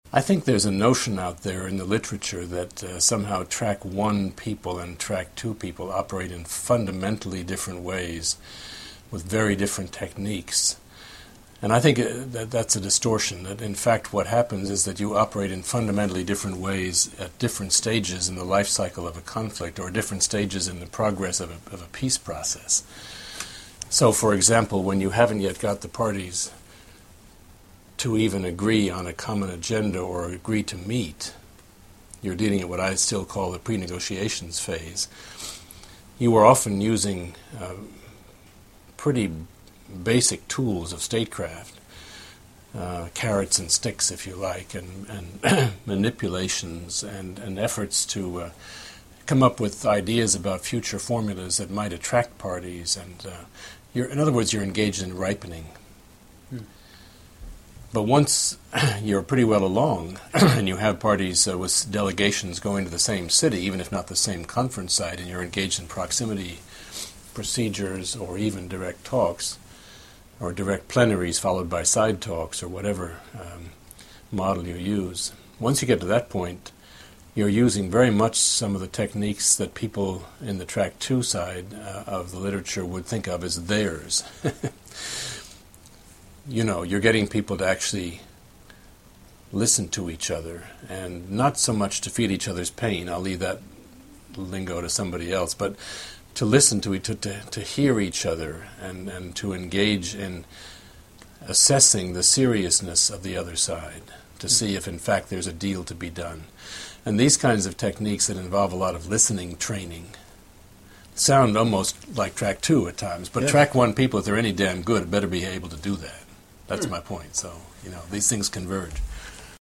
Chester Crocker
Interviewed